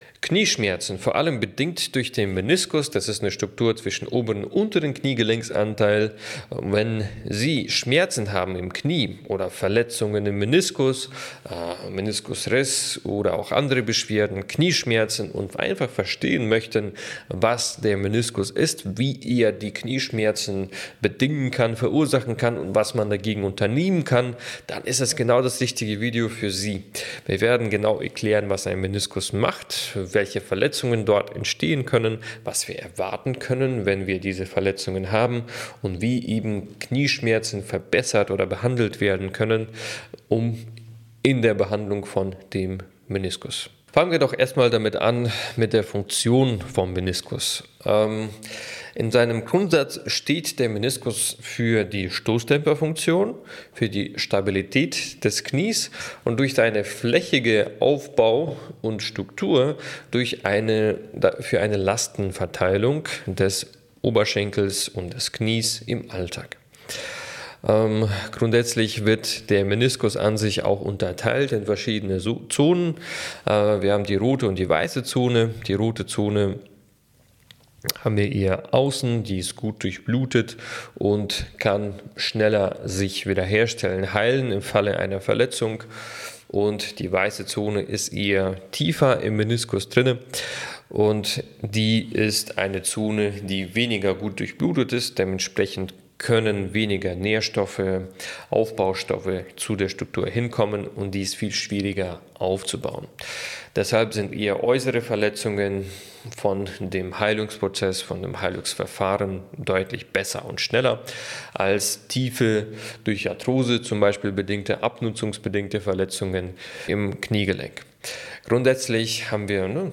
In diesem Gespräch wird die Bedeutung des Meniskus für die Kniegesundheit erläutert, einschließlich der Ursachen von Knieschmerzen, der verschiedenen Arten von Meniskusverletzungen und den Behandlungsmöglichkeiten. Es wird betont, wie wichtig Physiotherapie und ein gesunder Lebensstil für die Genesung und Prävention von Knieproblemen sind.